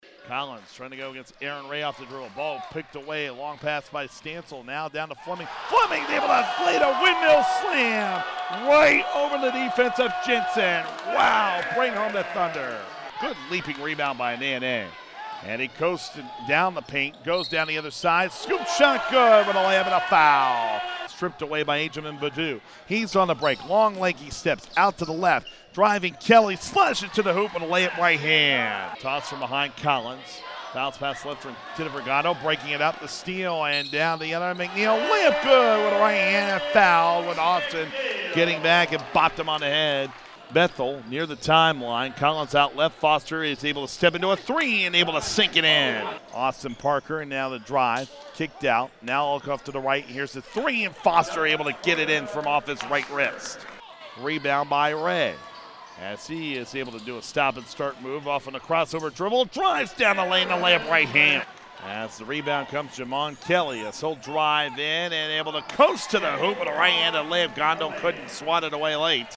Men’s Highlights
gccc-bethal-jv-highlights.mp3